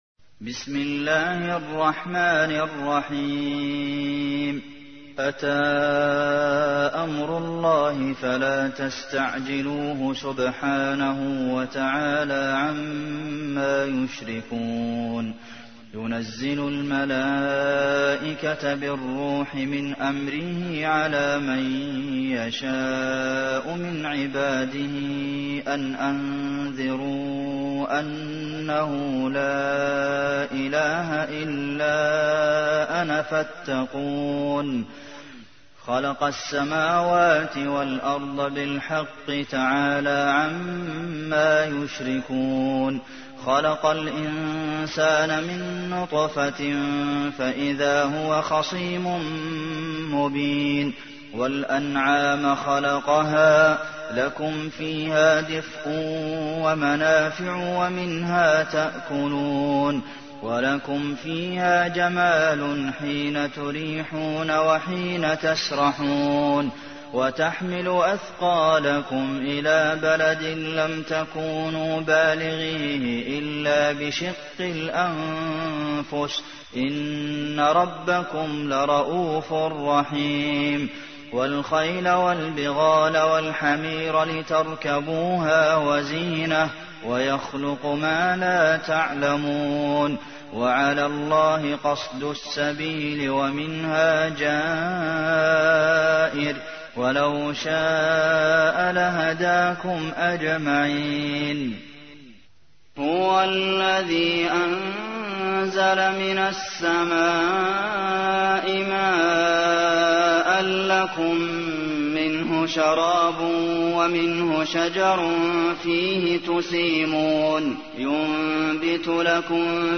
تحميل : 16. سورة النحل / القارئ عبد المحسن قاسم / القرآن الكريم / موقع يا حسين